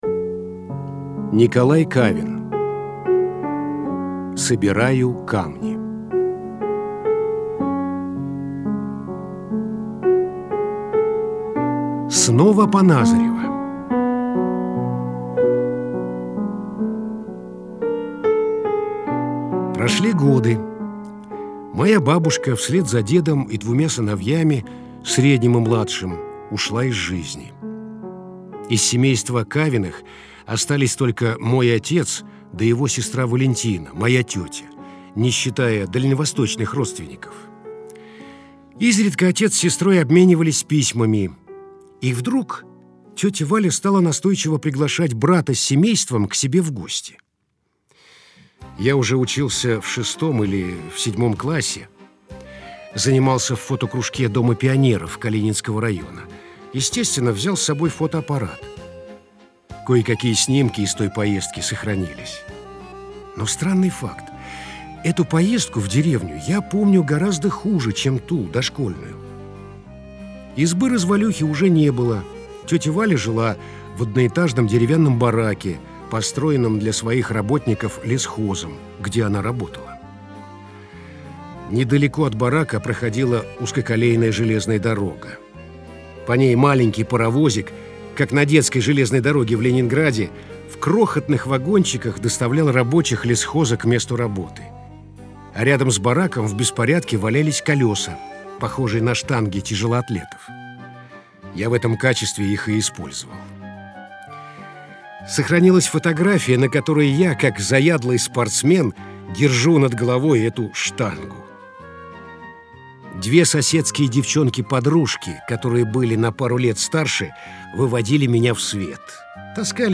Литературные чтения (20:45)